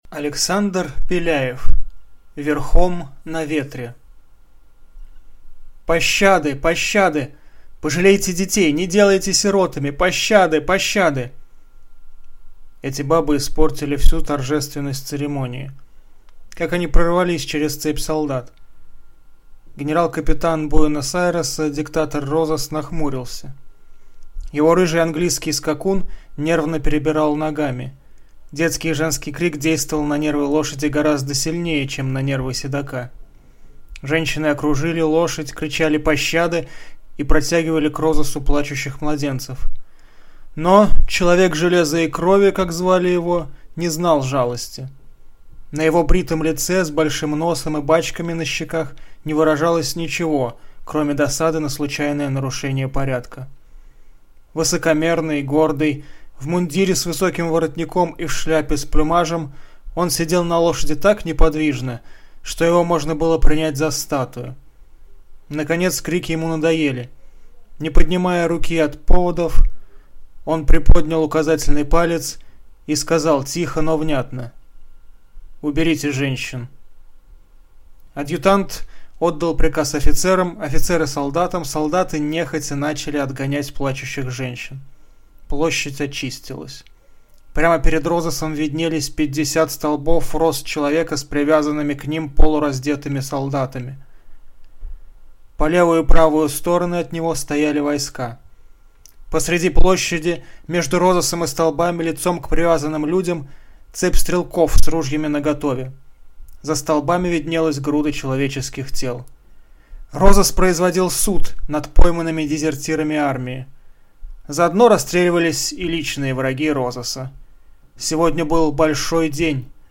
Аудиокнига Верхом на Ветре | Библиотека аудиокниг